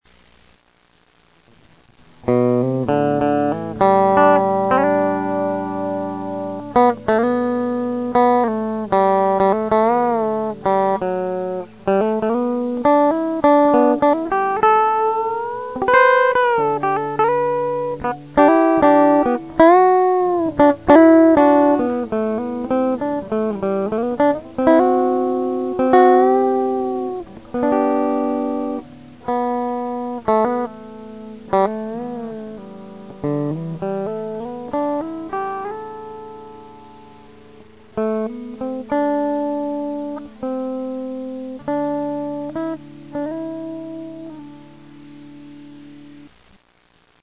(Solo)
Also use kind of a rake technique to get the right sound.